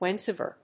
/(h)wénsoʊèvɚ(米国英語), wènsəʊévə(英国英語)/